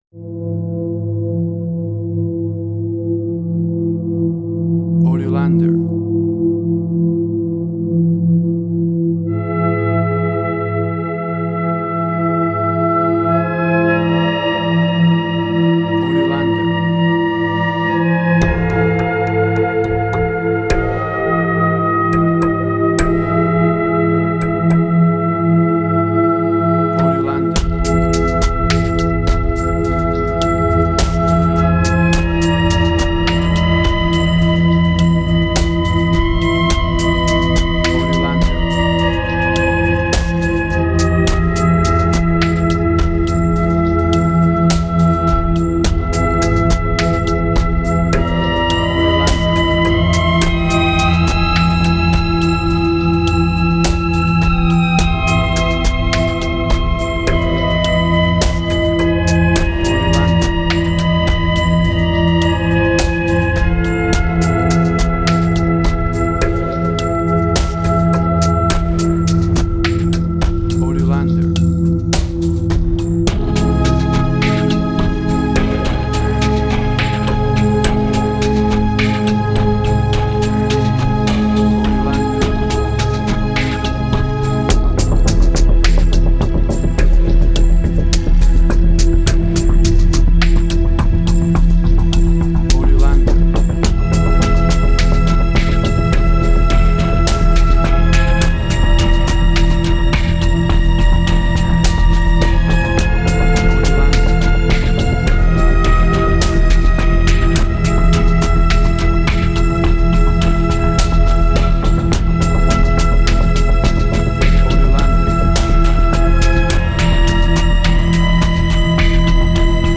Ambient Strange&Weird
Tempo (BPM): 105